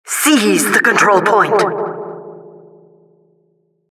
announcer_am_capincite03